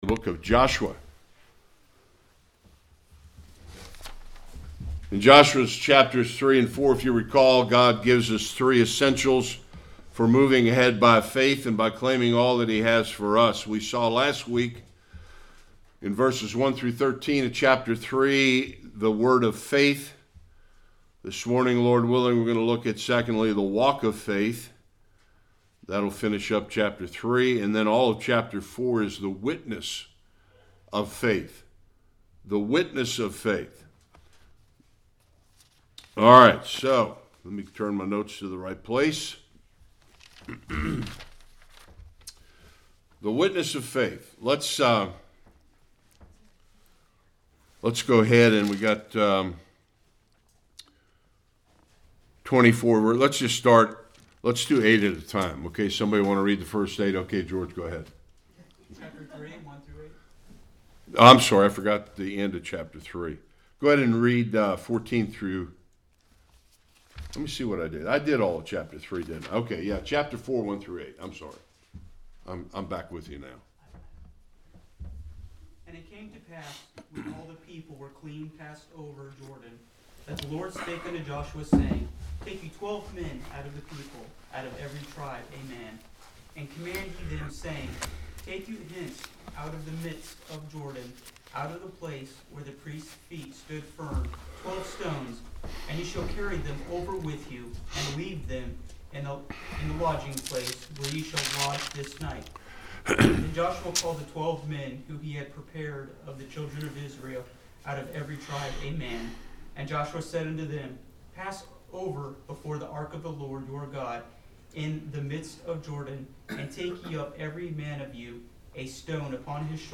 1-24 Service Type: Sunday School The significance of the 2 heaps of stones.